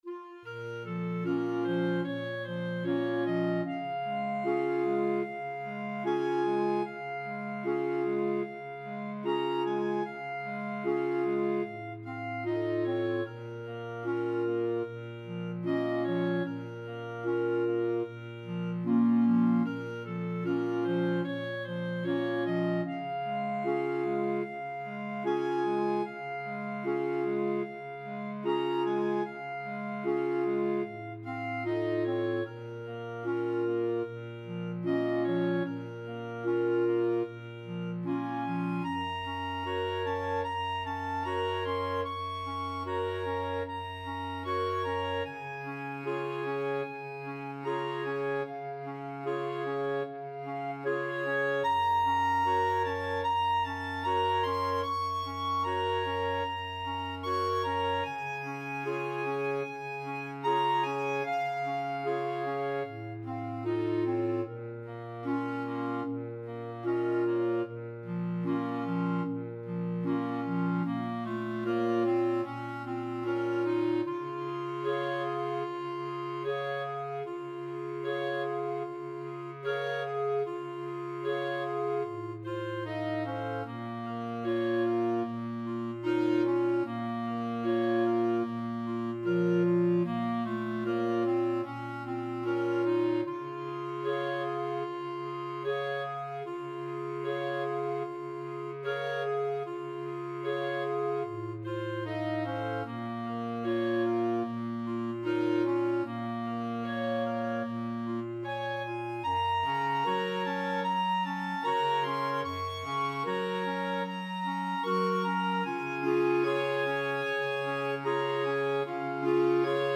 4/4 (View more 4/4 Music)
Molto espressivo = 150
Clarinet Choir  (View more Easy Clarinet Choir Music)
World (View more World Clarinet Choir Music)